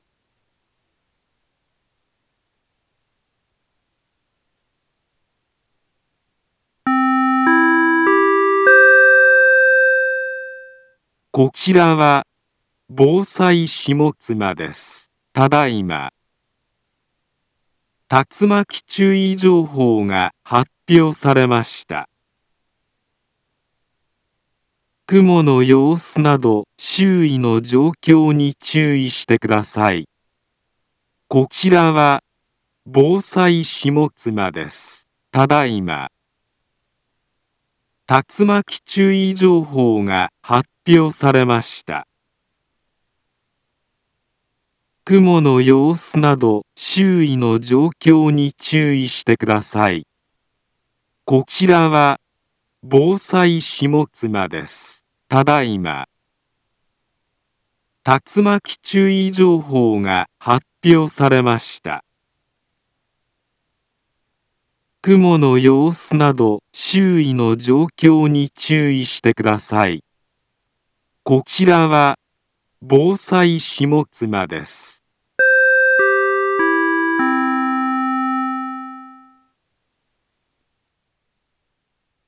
Back Home Ｊアラート情報 音声放送 再生 災害情報 カテゴリ：J-ALERT 登録日時：2021-07-10 18:15:25 インフォメーション：茨城県南部は、竜巻などの激しい突風が発生しやすい気象状況になっています。